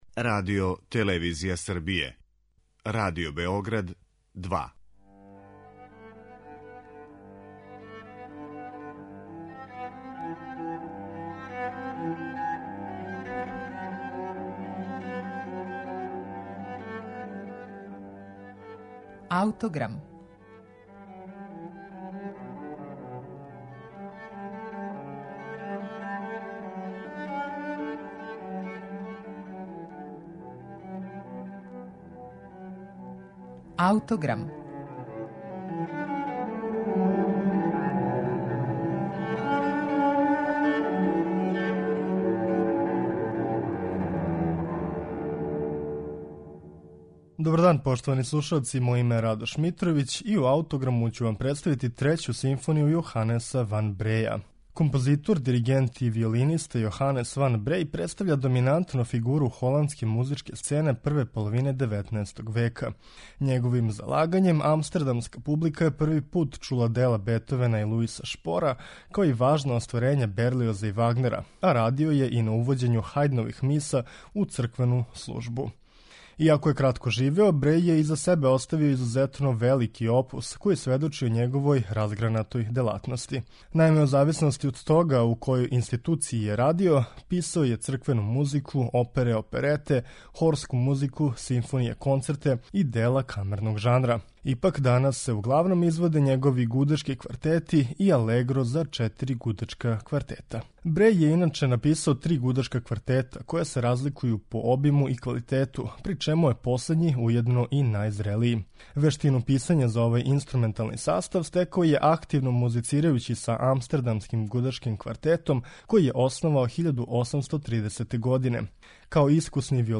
Слушаћемо Трећи квартет Јоханеса ван Бреја
У емисији Аутограм ћемо слушати Трећи квартет Јоханеса ван Бреја, у извођењу квартета Номос.